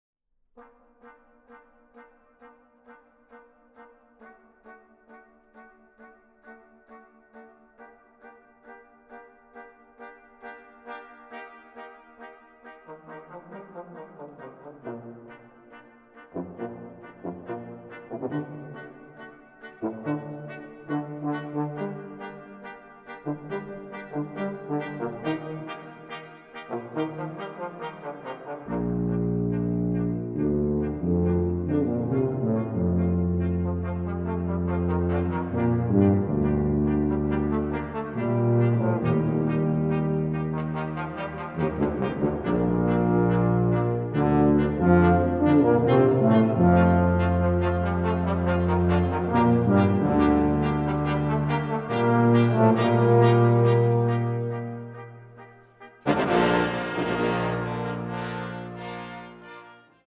Music for trombone choir and brass ensemble
Brass ensemble